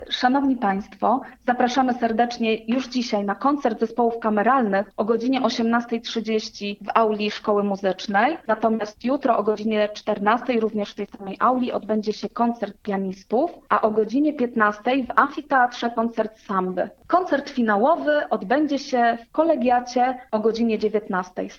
Wiadomości